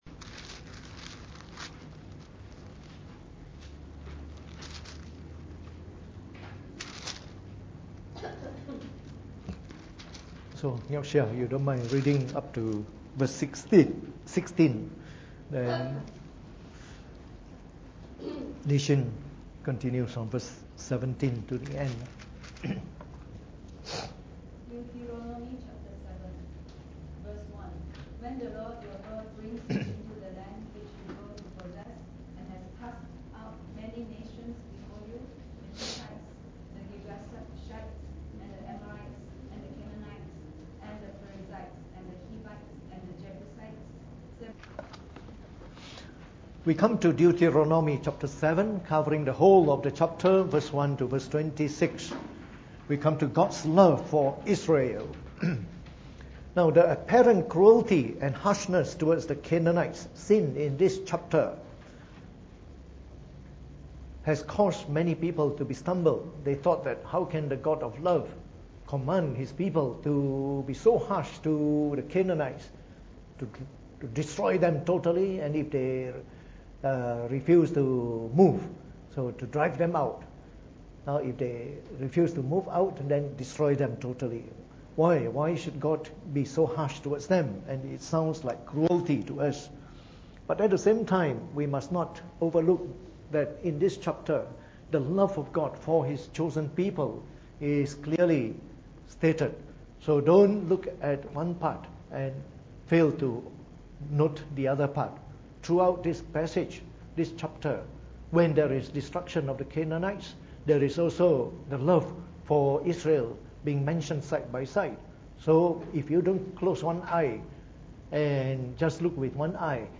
Preached on the 28th of February 2018 during the Bible Study, from our series on the book of Deuteronomy.